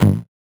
CS_VocoBitB_Hit-13.wav